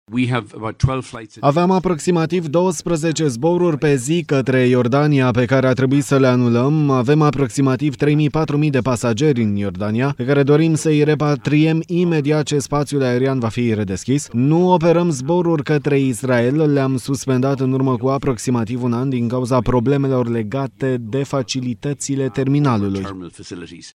Cel puțin patru mii de pasageri ai companiei Ryanair așteaptă să fie repatriați din Iordania. Cifra a fost avansată de CEO-ul companiei – Michael O’Leary – în cadrul unei conferințe de presă în Varșovia.
03mar-15-CEO-Ryanair-pasagerii-blocati-in-Iordania-TRADUS.mp3